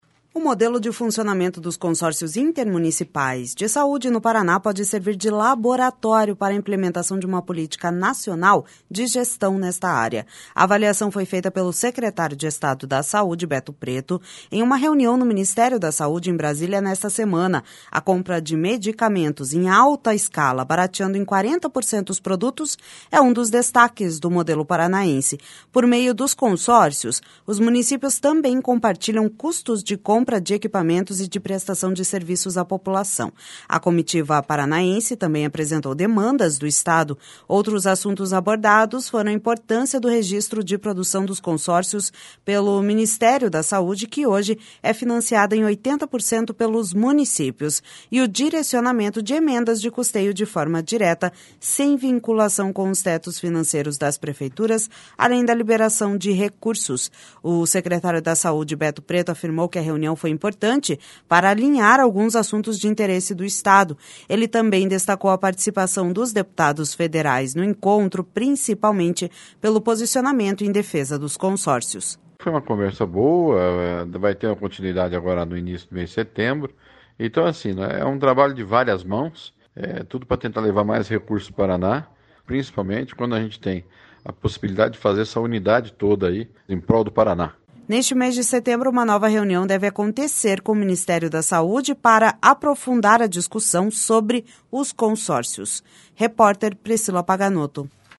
Ele também destacou a participação dos deputados federais no encontro, principalmente pelo posicionamento em defesa dos consórcios.// SONORA BETO PRETO//Neste mês de setembro, uma nova reunião deve acontecer com o Ministério da Saúde para aprofundar a discussão sobre os consórcios.